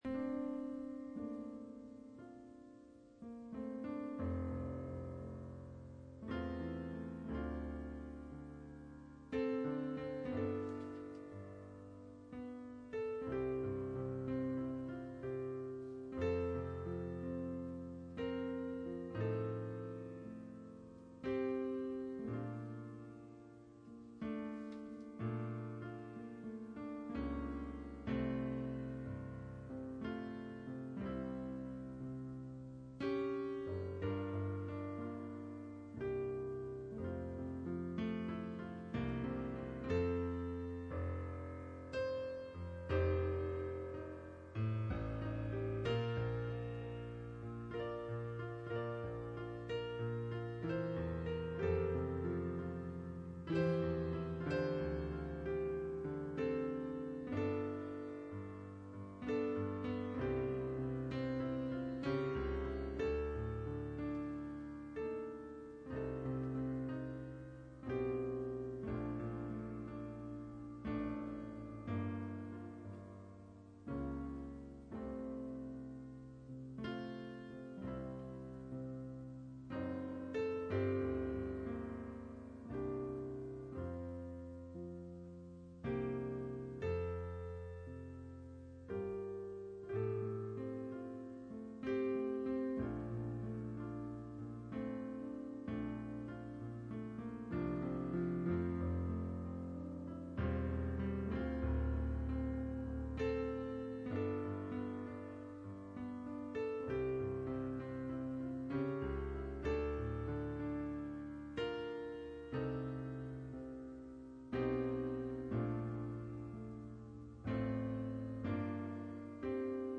Pastor Service Type: Sunday Morning %todo_render% « Theophany